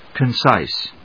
音節con・cise 発音記号・読み方
/kənsάɪs(米国英語)/